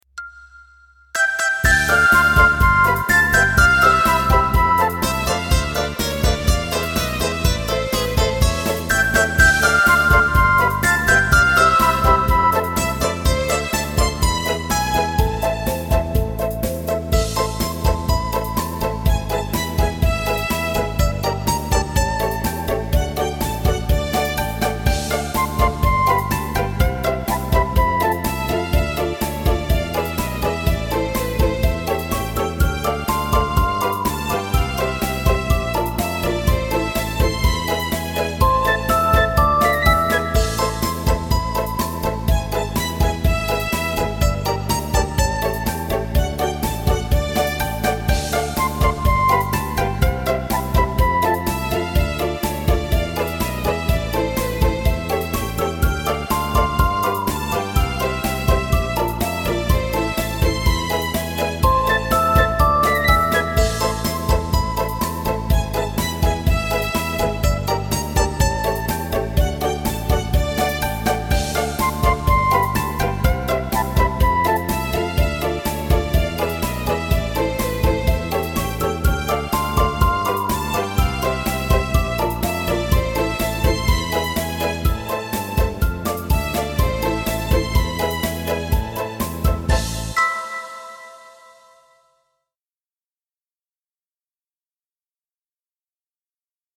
• Категория: Детские песни
караоке